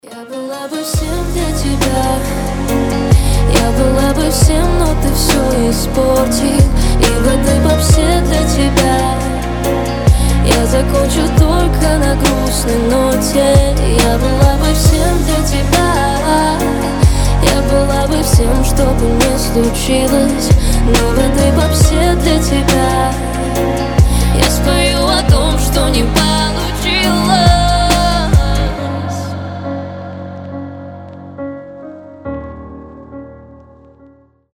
• Качество: 320, Stereo
красивые
грустные
спокойные
пианино
медленные